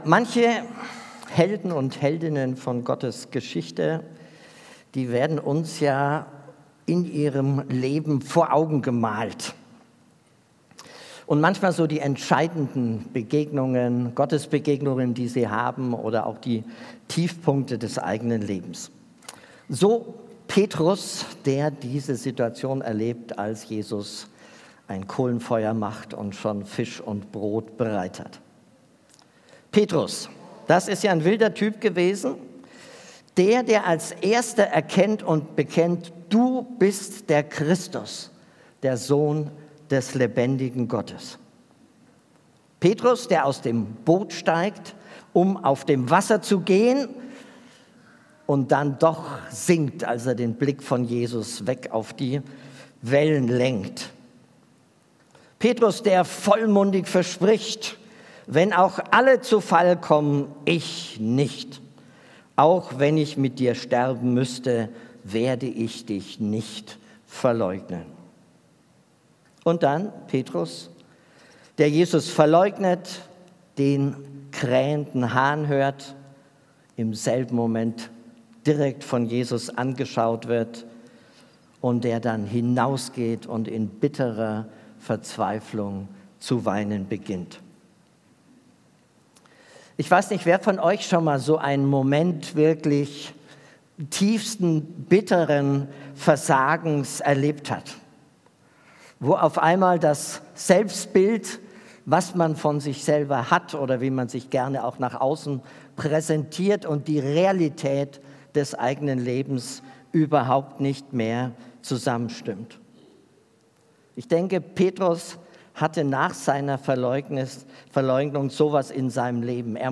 Gottesdienst
Predigt